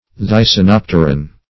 Thysanopteran \Thy`sa*nop"ter*an\, n.
thysanopteran.mp3